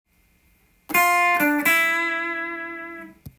エレキギターで弾ける【Gミクソリディアンフレーズ集】オリジナルtab譜つくってみました
③のフレーズは②とは逆にミの音（１３ｔｈ）に着地するので
緊張感があるフレーズです。
非常に危険でカッコいい雰囲気と隣り合わせです。